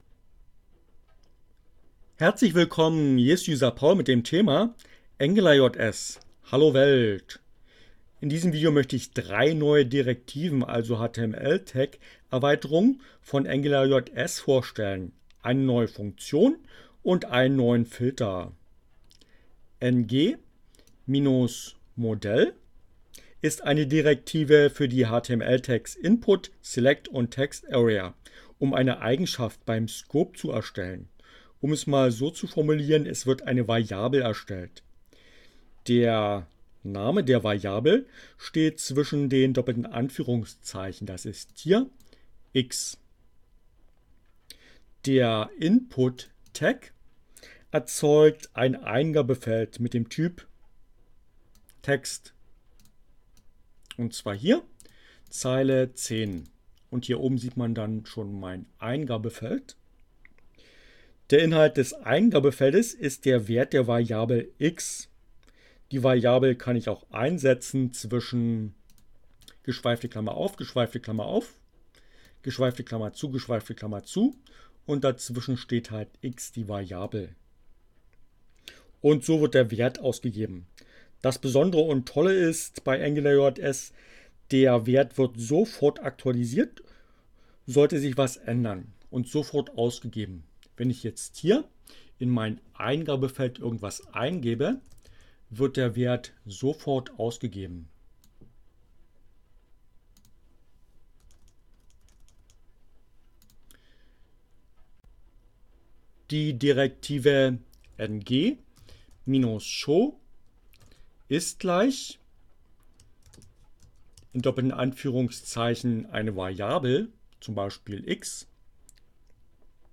Tags: CC by, Linux, Neueinsteiger, ohne Musik, screencast, Web, HTML, Javascript, angularjs